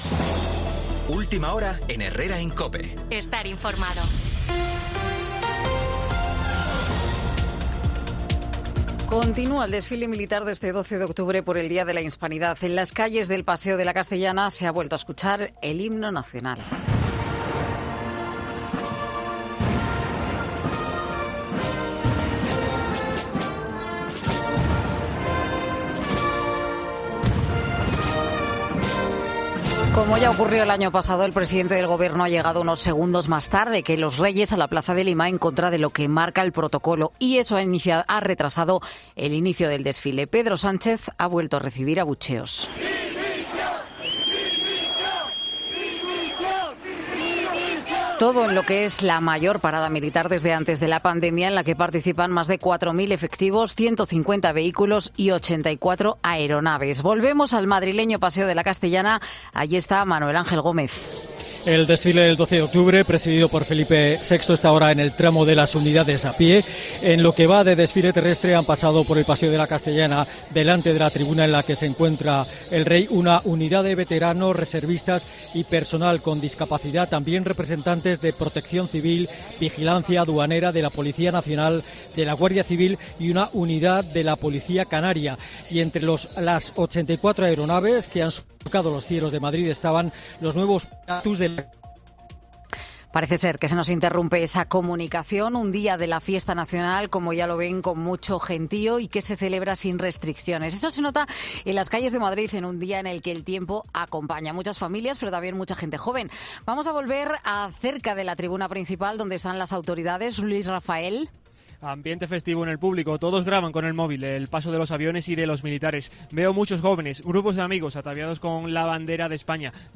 Sánchez no frena los abucheos pese a llegar tarde al desfile del 12-O. Escucha las noticias de las 12